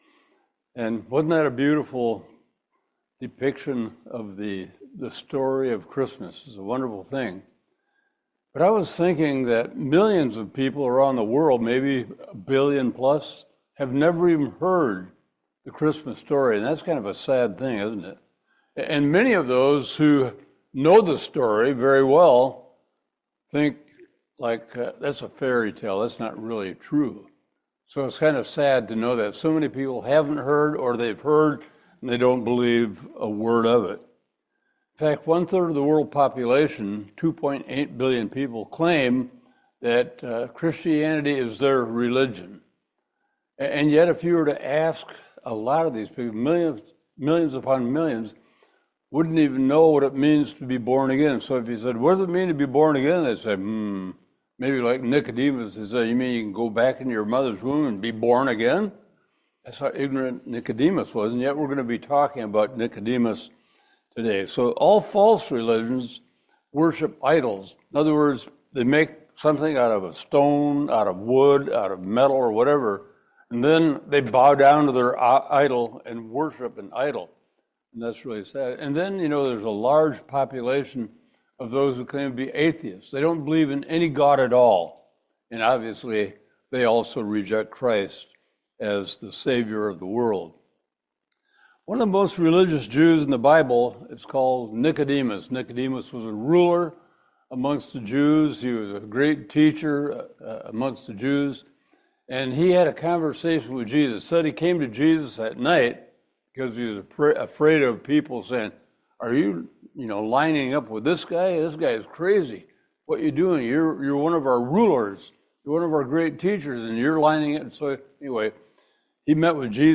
John 3:16-21 Service Type: Family Bible Hour Jesus Christ came into the world to save sinners.